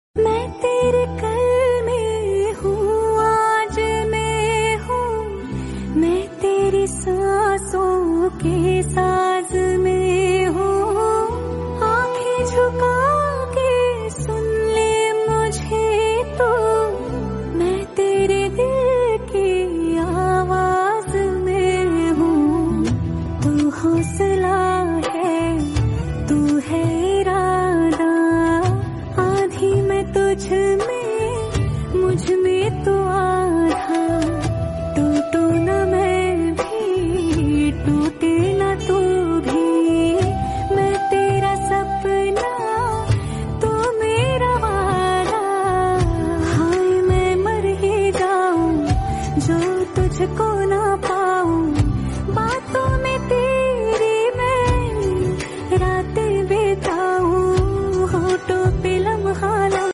Female Version